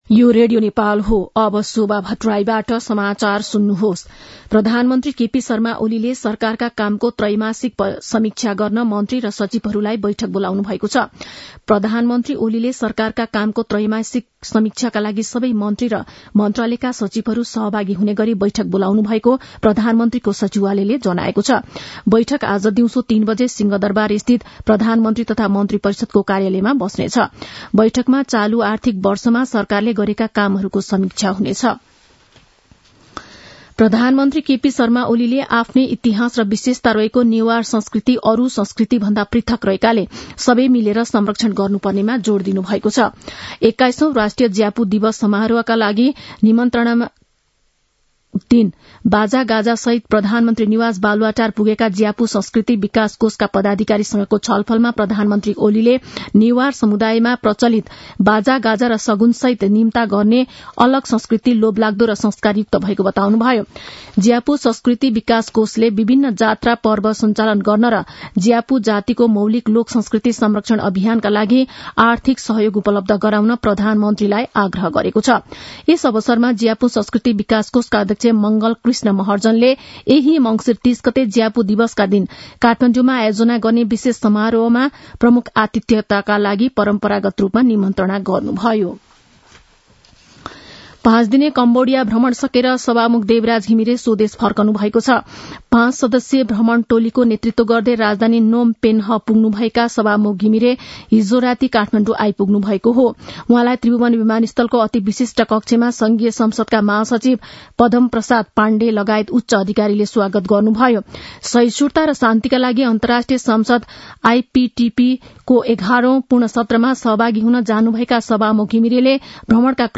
मध्यान्ह १२ बजेको नेपाली समाचार : १३ मंसिर , २०८१
12-am-nepali-news-1-9.mp3